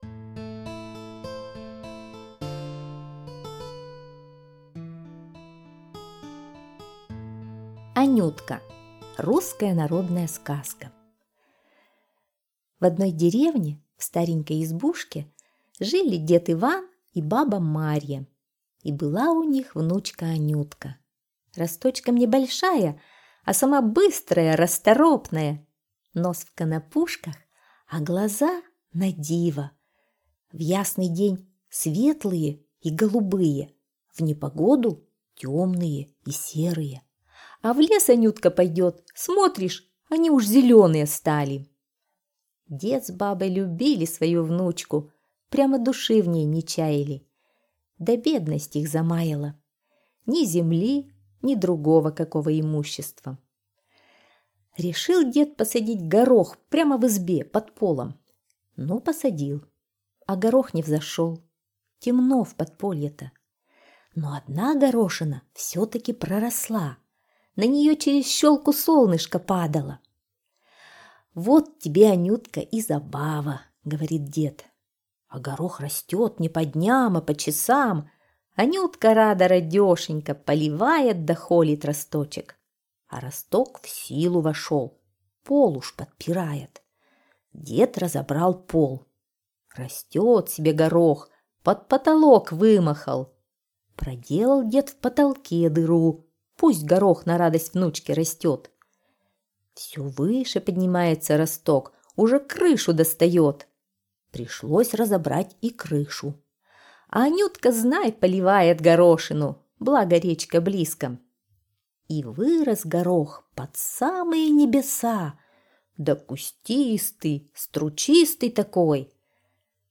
Анютка - русская народная аудиосказка - слушать онлайн